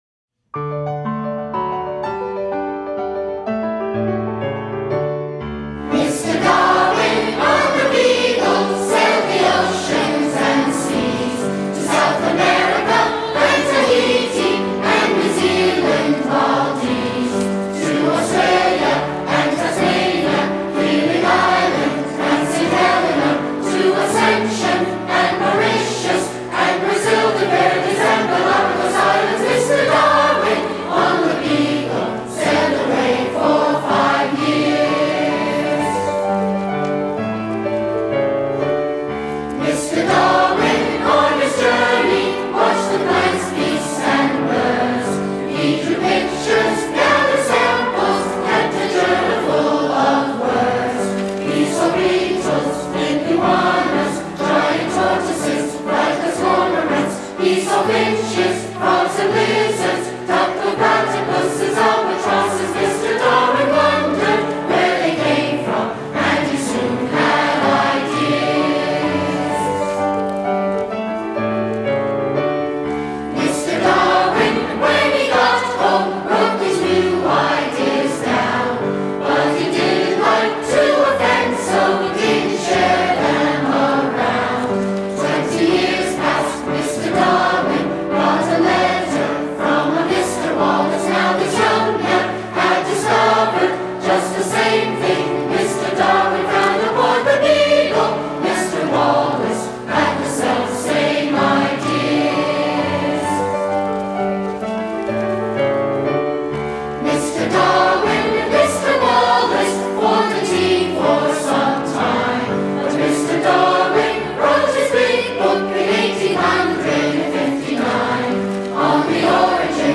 Below you can hear the 2007 Festival Chorus performing Lifetime: Songs of Life and Evolution.
Performed with members of the Haggerty School Chorus.